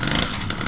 Chainsaw
Idling